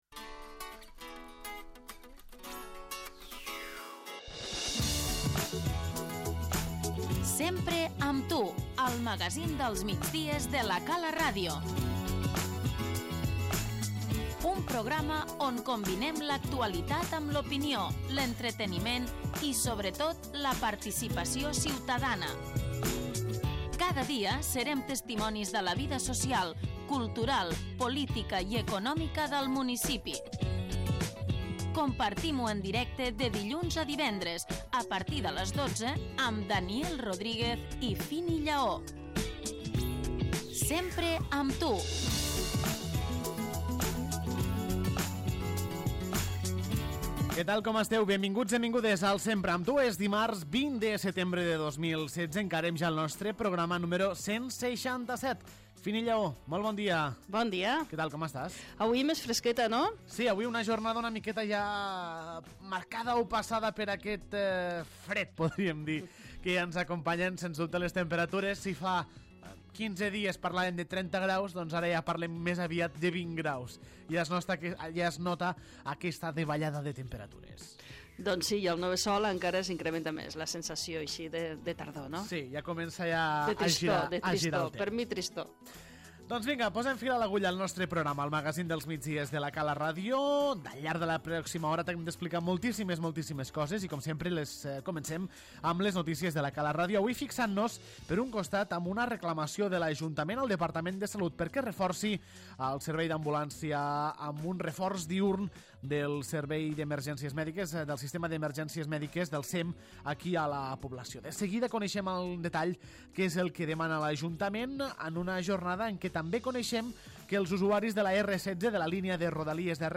Encetem un nou magazín dels migdies a La cala RTV.